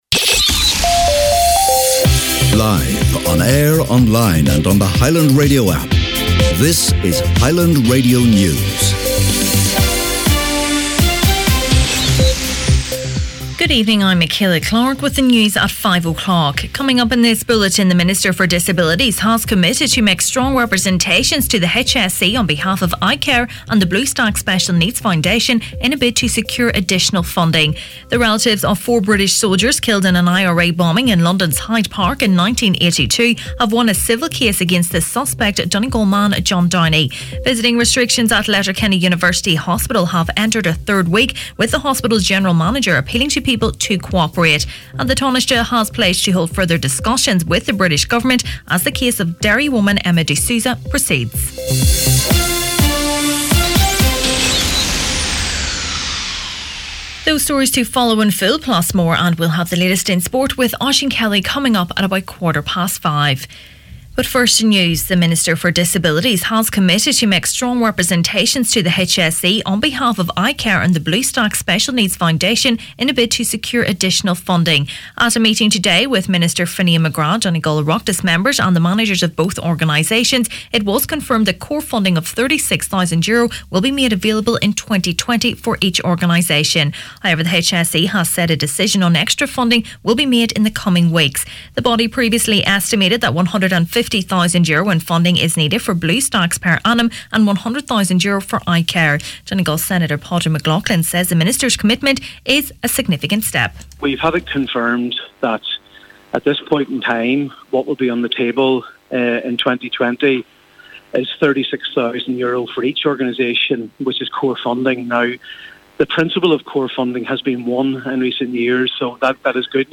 Main Evening News, Sport and Obituaries Wednesday December 18th